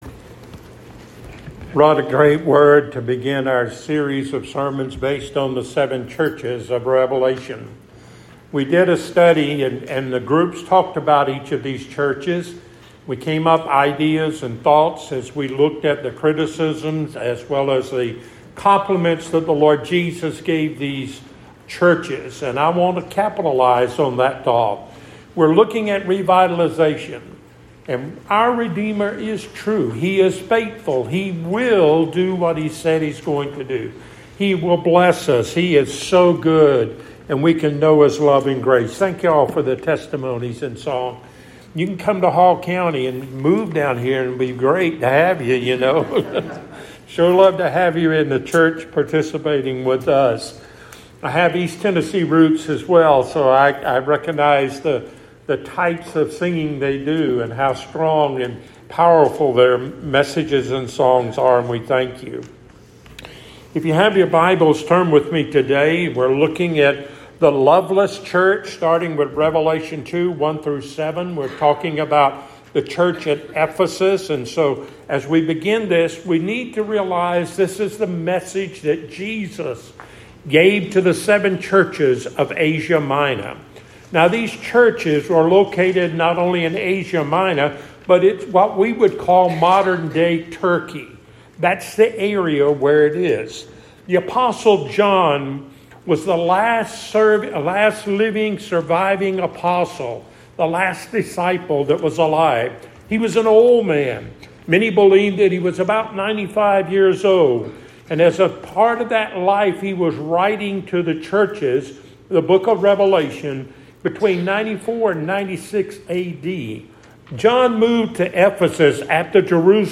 Passage: Revelation 2:1-7 Service Type: Sunday Morning